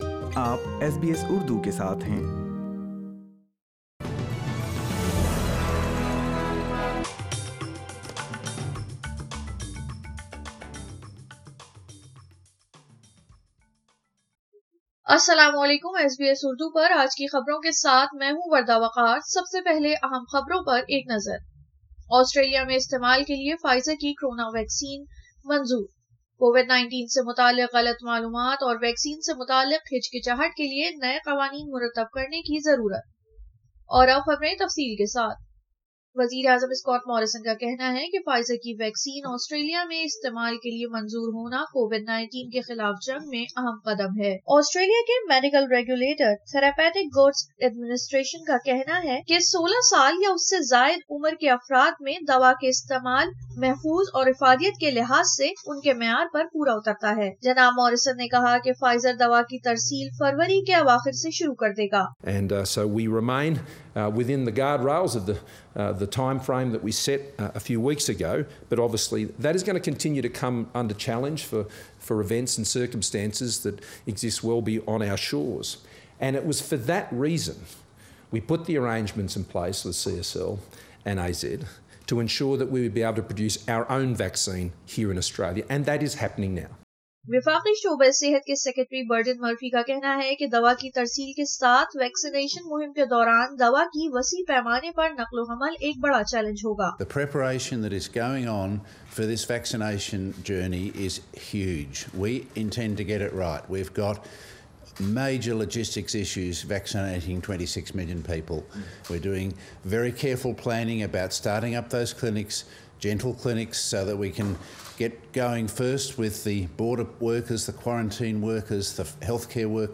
فائزر کی ویکسین آسٹریلیا میں استعمال کے لئے منظور، آسٹریلیا میں کووڈ 19 کا پہلا کیس سامنے آئے سال گزر گیا ۔ سنئے اردو خبریں